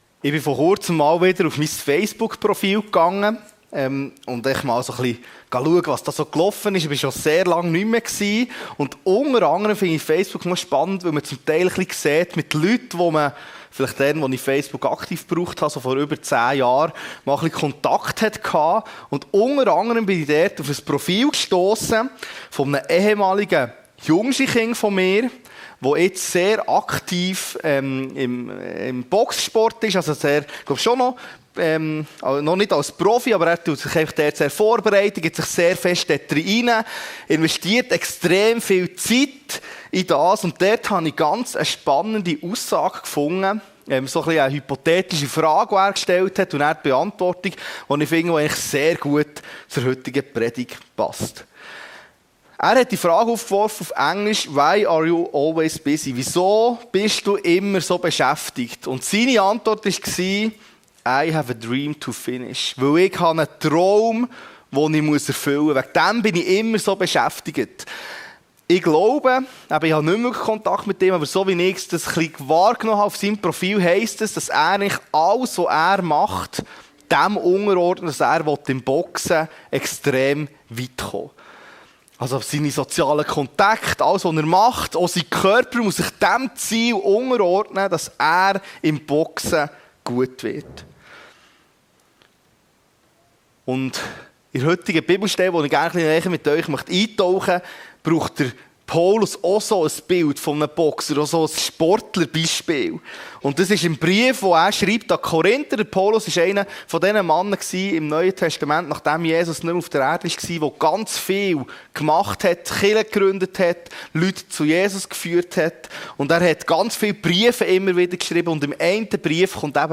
Nachfolge – ein Wettrennen?! - seetal chile Predigten